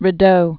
(rĭ-dō)